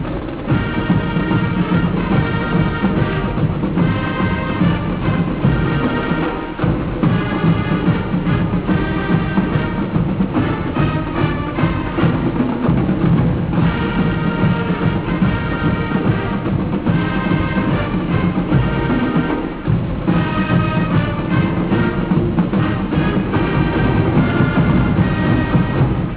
The Elyria High School Pioneer Marching Band features 12 tubas and the "K.A.C.P." percussion section.